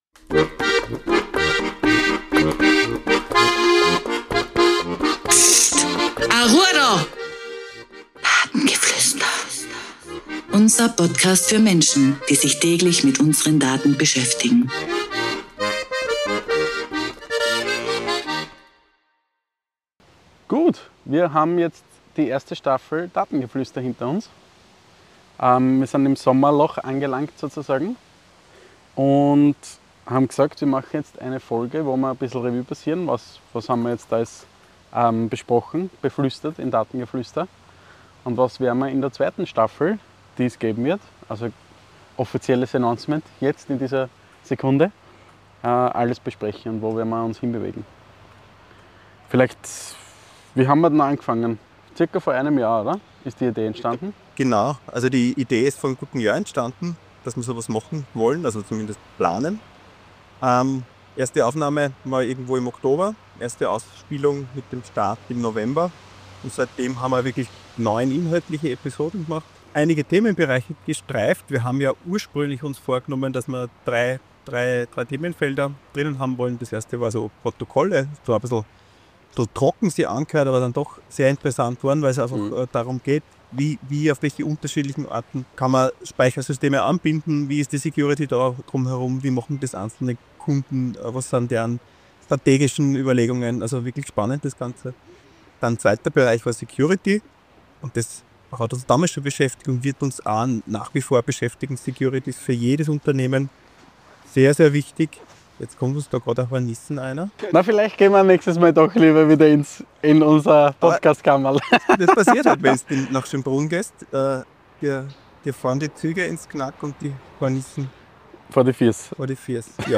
Zum Staffelfinale haben wir uns in einen Park gesetzt - deshalb ist die Tonqualität nicht in gewohnter Qualität. Wir hoffen das Vogelgezwitscher lenkt nicht zu sehr vom Datengeflüster ab!